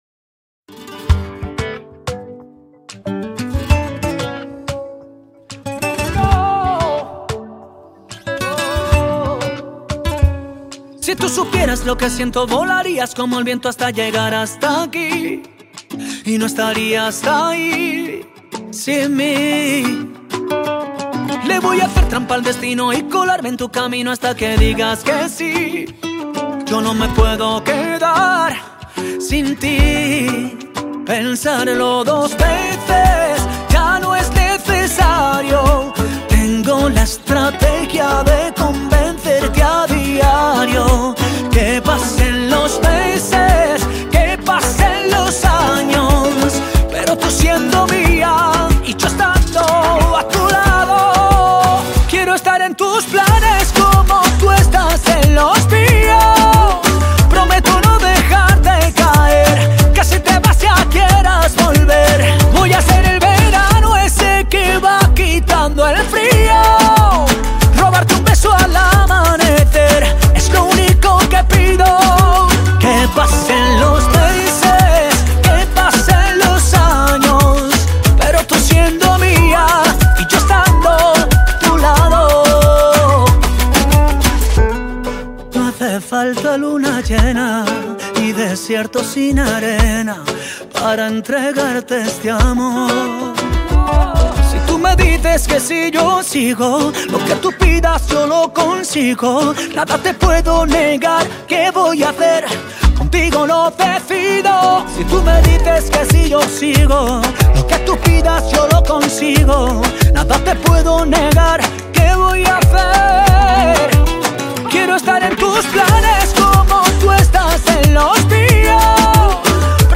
que vuelve a la rumba latina.